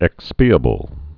(ĕkspē-ə-bəl)